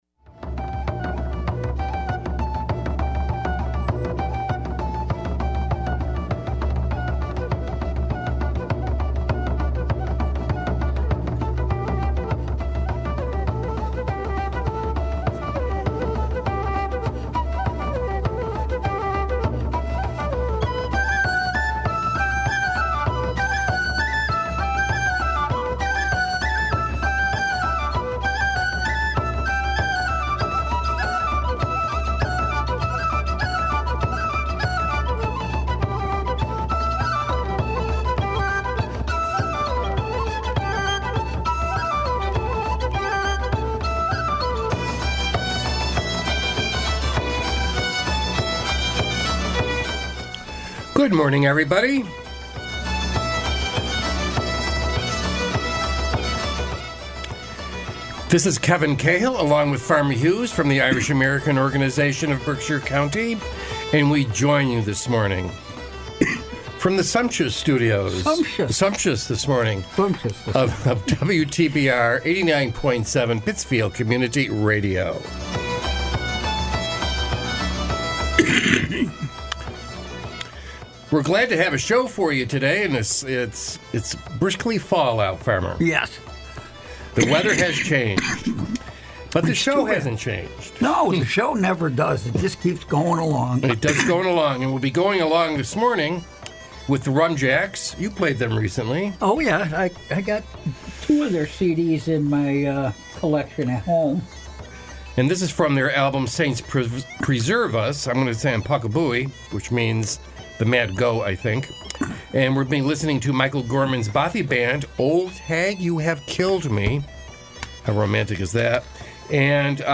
Broadcast every Sunday morning at 10am on WTBR.